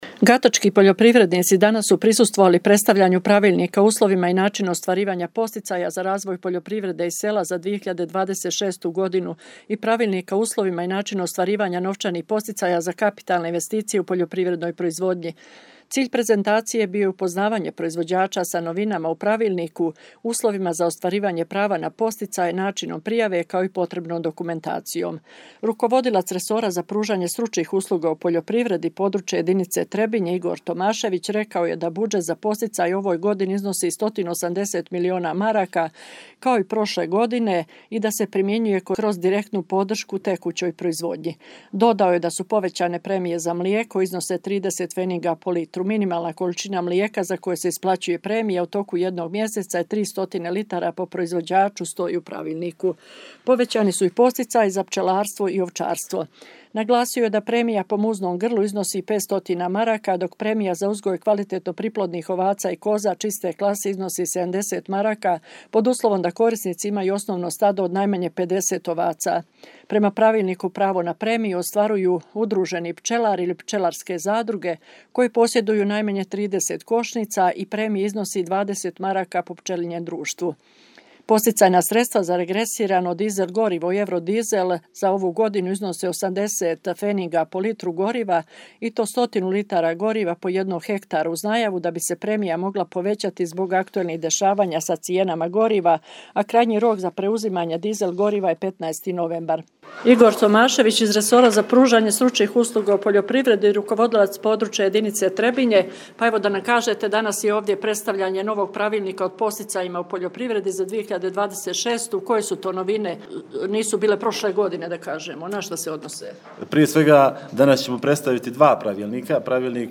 Prezentaciju su održali predstavnici Ministarstva poljoprivrede, šumarstva i vodoprivrede Republike Srpske i Područne jedinice Resora za pružanje stručnih usluga u poljoprivredi iz Trebinja, koji su odgovarali na pitanja prisutnih i dali dodatna pojašnjenja.
Prezentacija-Pravilnika.mp3